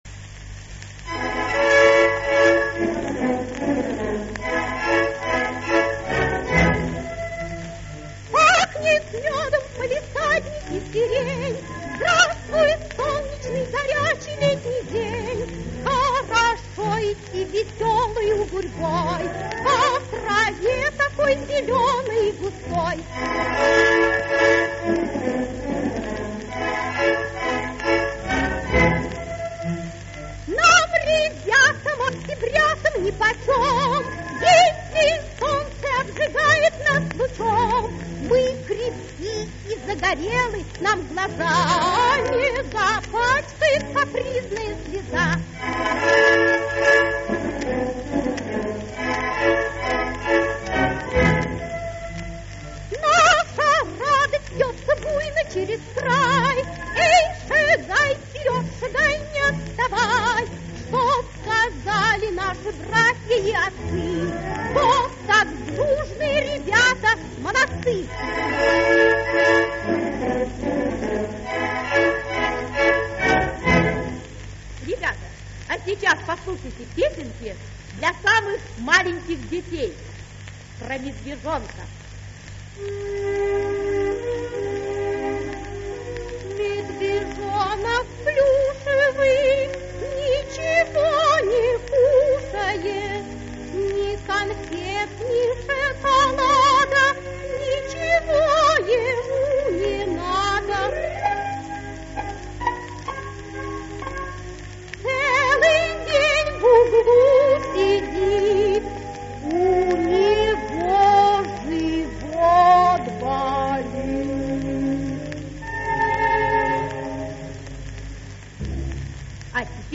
Пионерские песни
Три пионерские песенки.